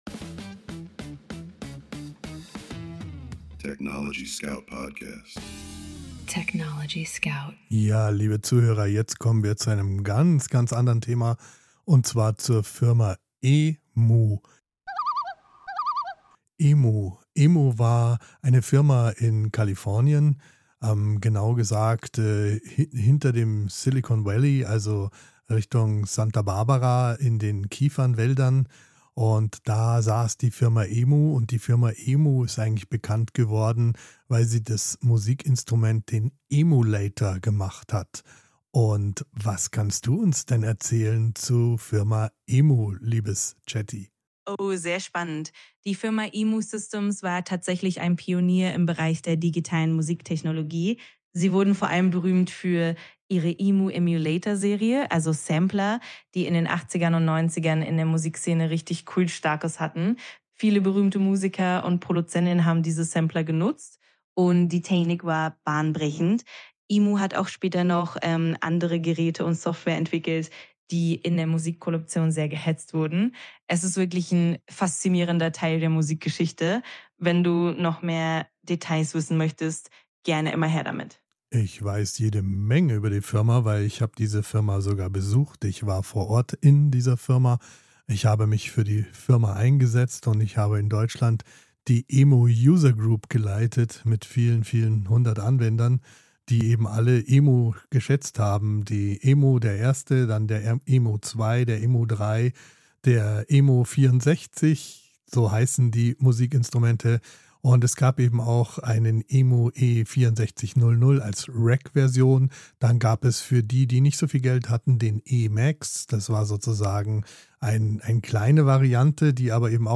Mensch und KI sprechen miteinander – nicht